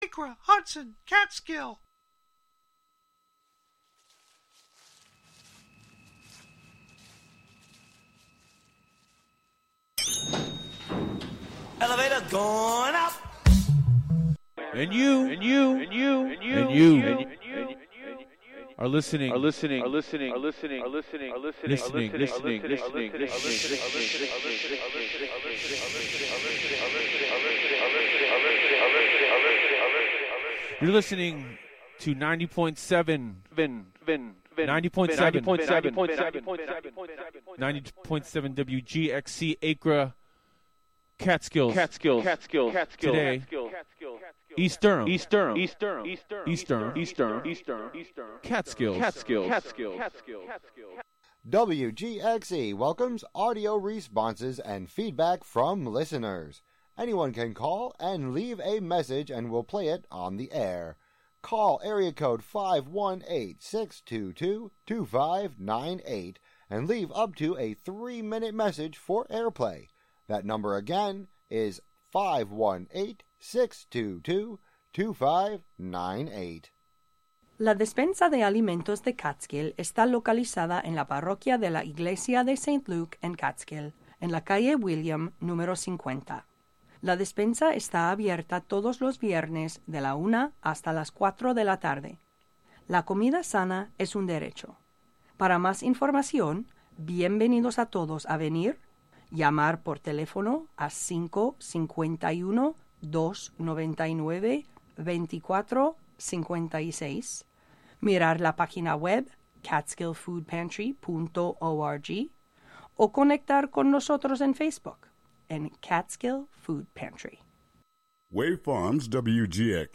Each broadcast features candid conversations with both professional and aspiring artists, uncovering the heart of their creative process, the spark of their inspiration, and the journey that brought them into the art scene. From painters and sculptors to musicians and writers, we celebrate the richness of artistic expression in our community and beyond.